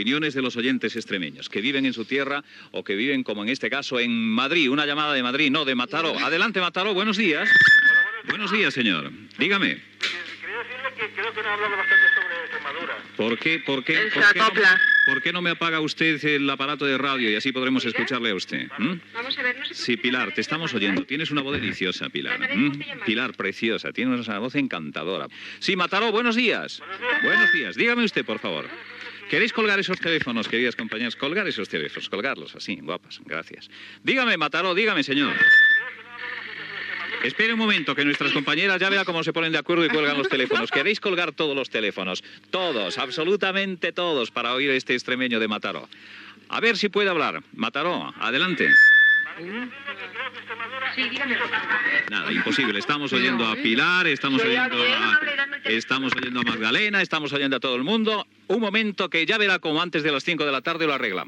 Pas als oients amb interferències i acoplaments telefòniques
Entreteniment